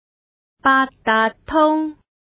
字詞： 八達通(baat3 daat6 tung1) octopus 解釋： 香港通用的電子收費系統，使用八達通卡或產品，便可於公車、零售商店等輕鬆付款。